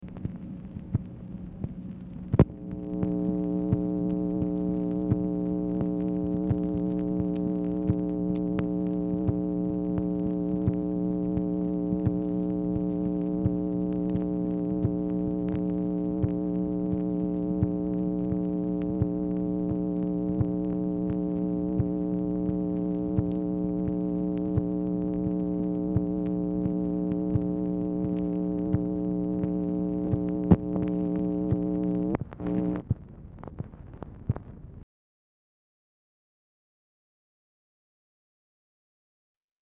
Telephone conversation # 8680, sound recording, MACHINE NOISE, 8/30/1965, time unknown | Discover LBJ
Format Dictation belt
Specific Item Type Telephone conversation